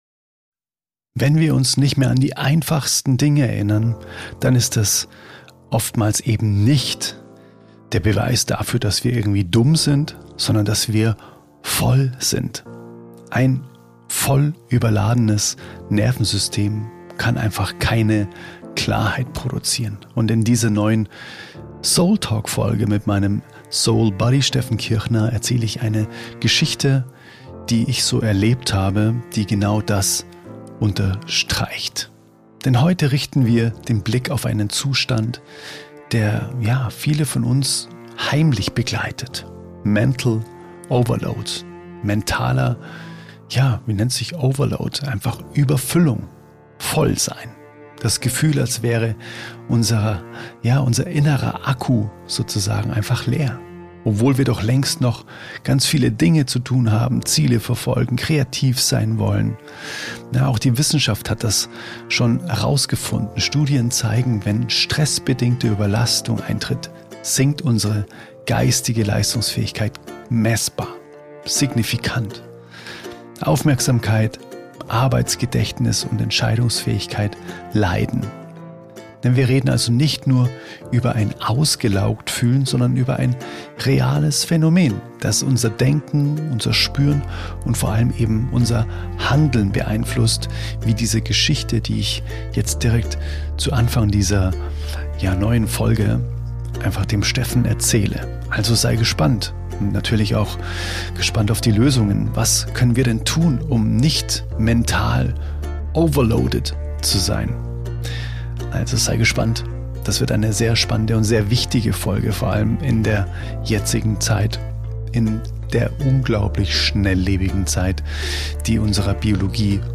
[SOULTALK] Unglaublich! Wie Stress uns ausbremst – und was wir tun können | Gespräch